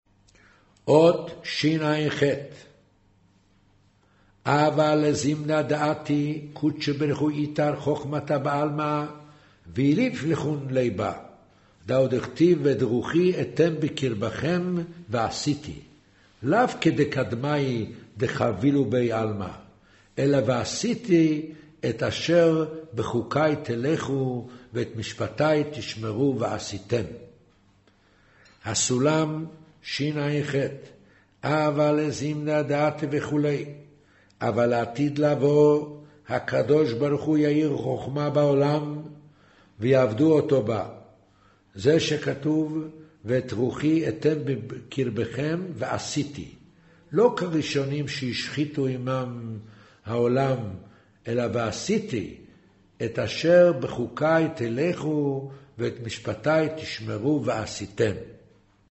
קריינות זהר